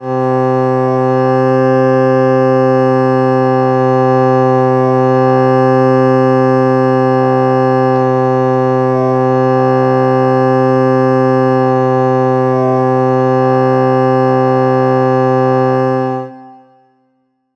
Roncón de gaita gallega en escala diatónica tocando la nota C
roncón
gaita